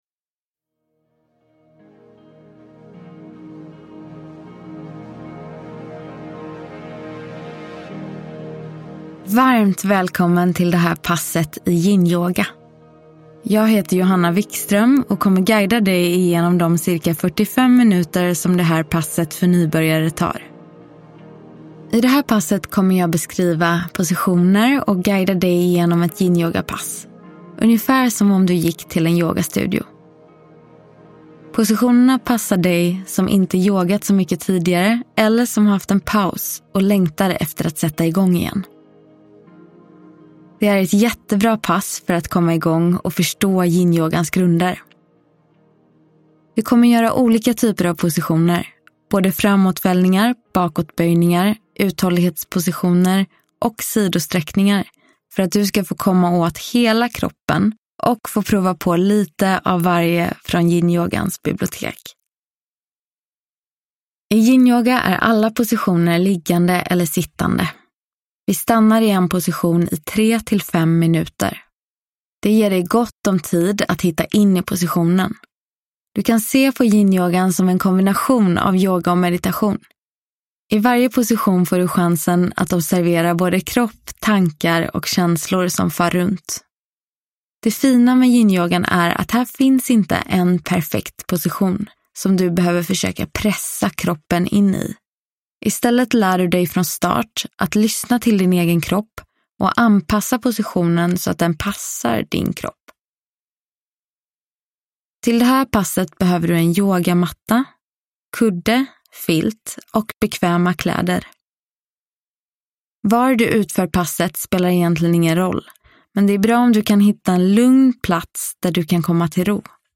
Yinyoga - Pass för nybörjare – Ljudbok